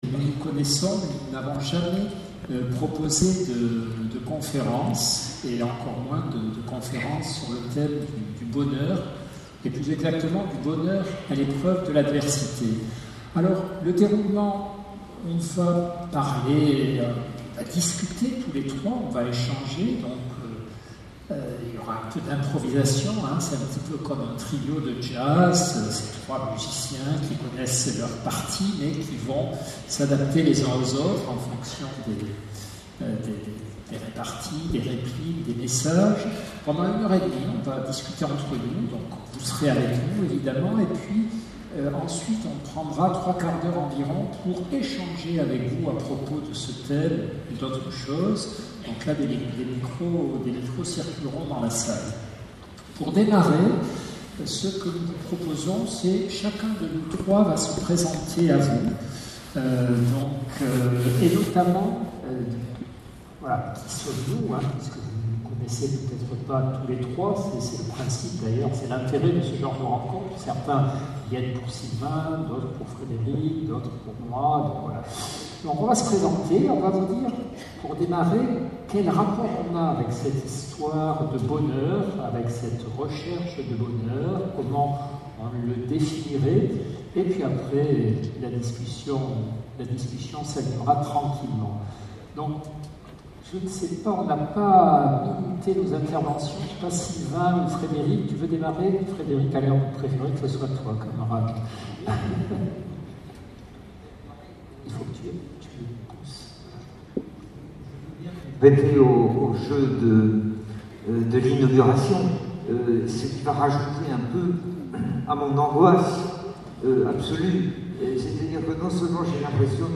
Audio salle conf sur le bonheur